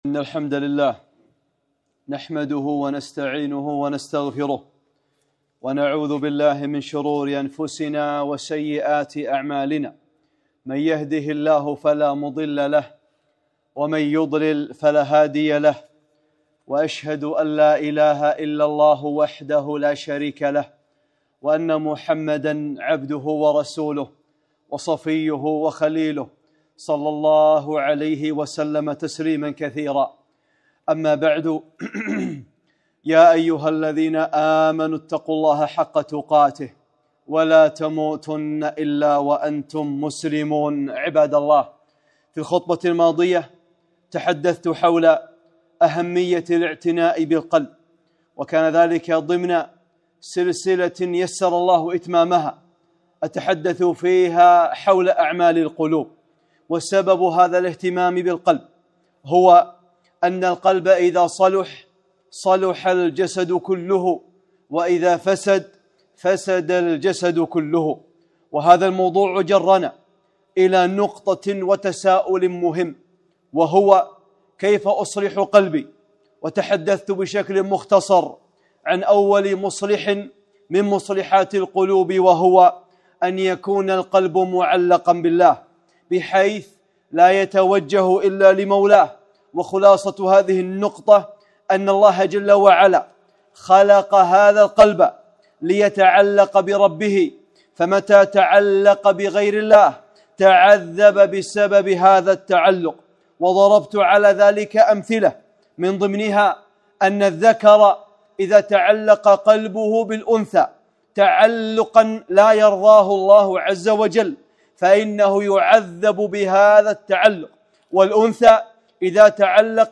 خطبة - (2) تعليق القلب بالله | سلسلة أعمال القلوب